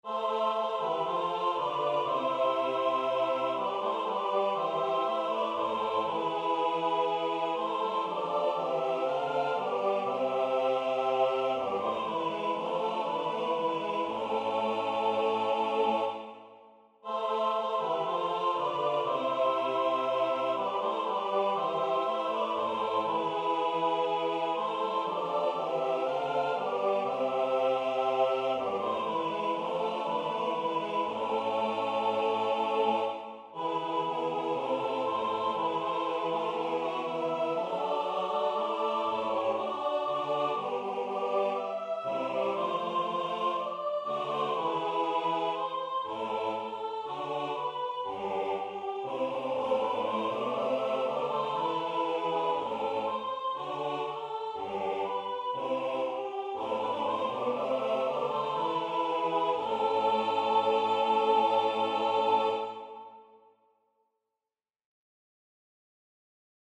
Number of voices: 4vv Voicing: SATB Genre: Secular, Madrigal